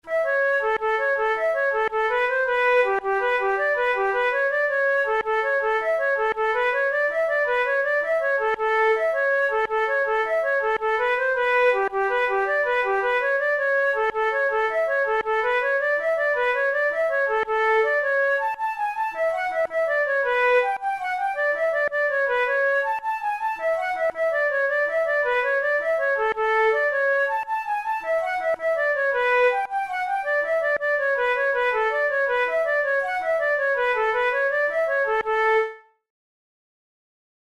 InstrumentationFlute solo
KeyA major
Time signature6/8
Tempo108 BPM
Jigs, Traditional/Folk
Traditional Irish jig